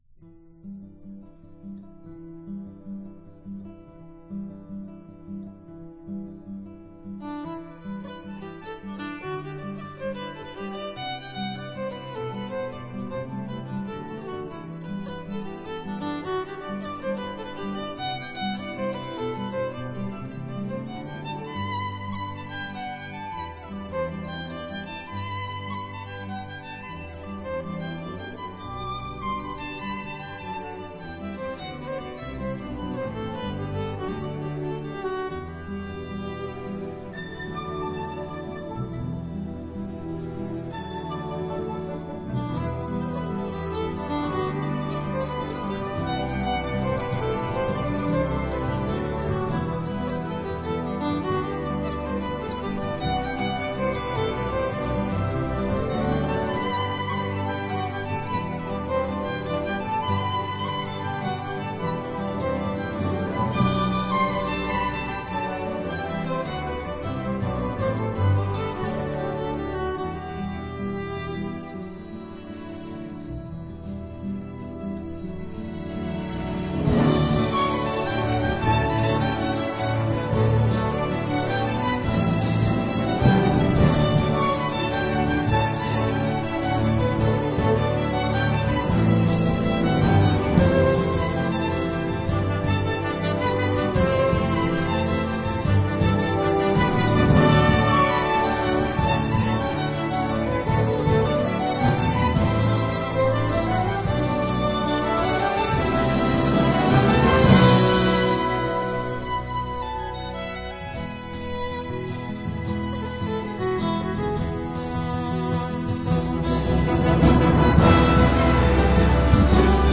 是一个不可多得的极品发烧录音。